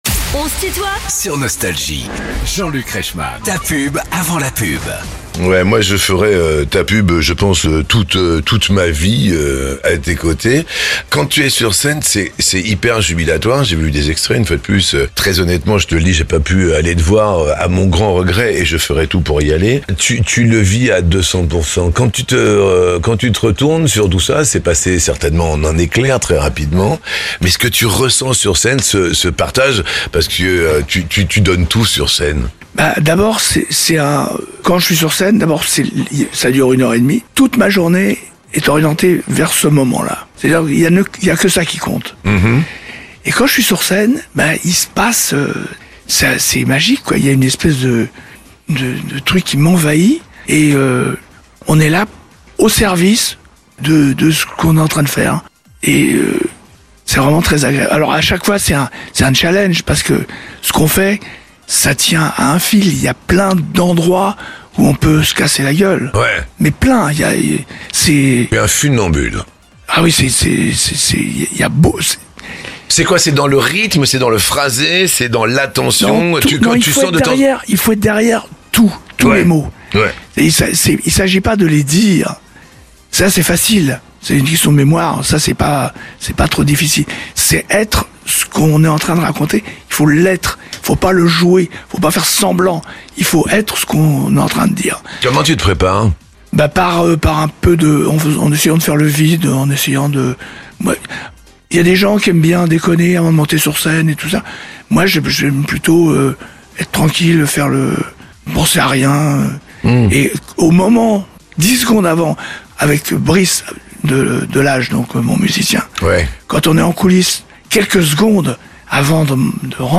Les interviews
Les plus grands artistes sont en interview sur Nostalgie.